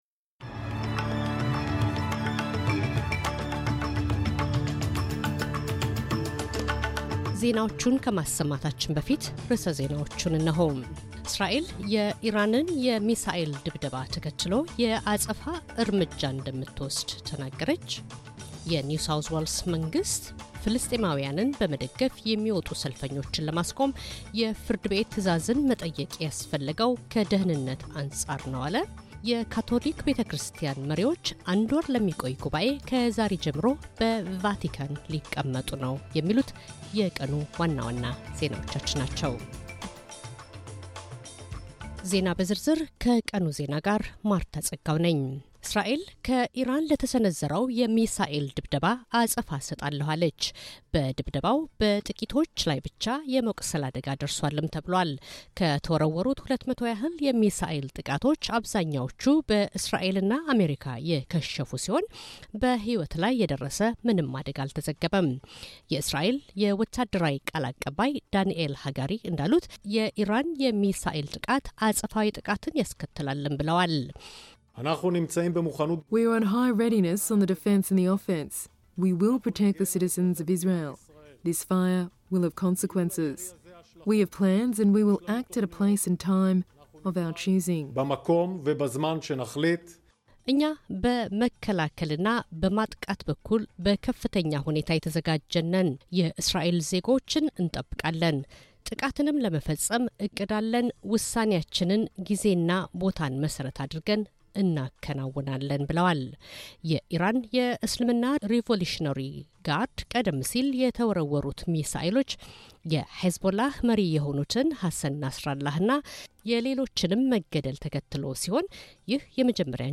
ዜና